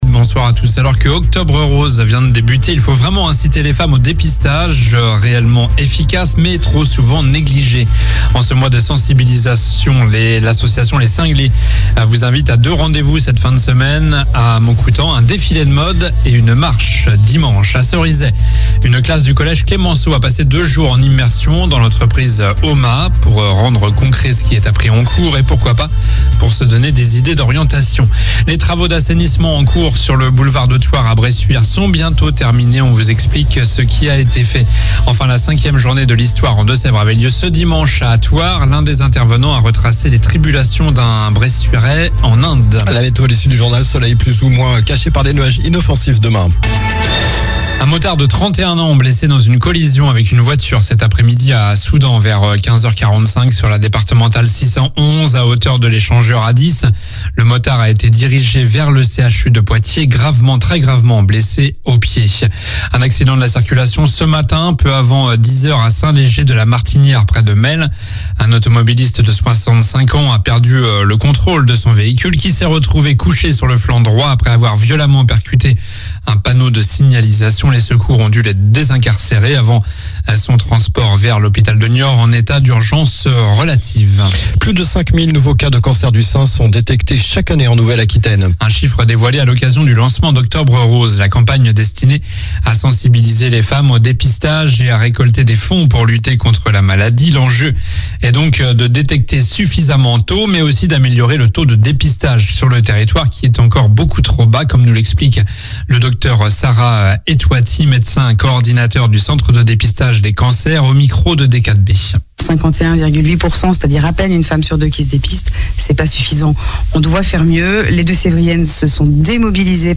Journal du mardi 3 octobre (soir)